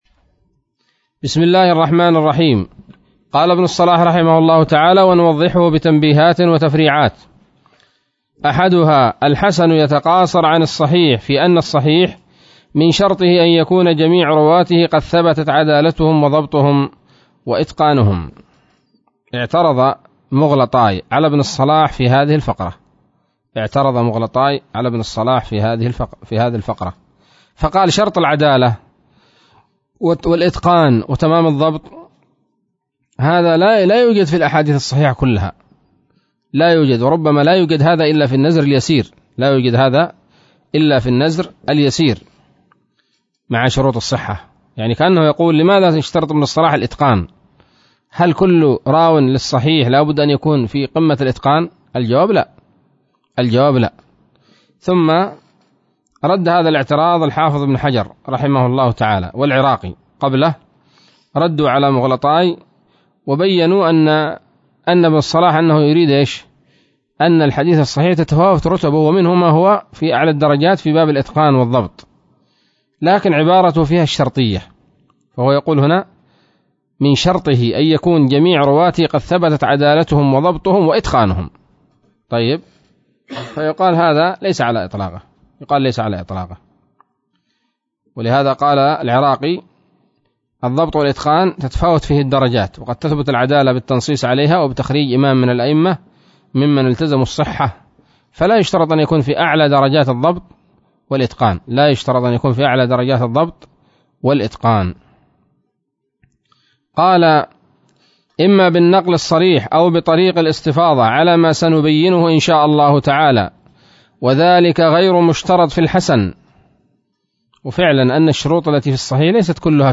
الدرس الرابع عشر من مقدمة ابن الصلاح رحمه الله تعالى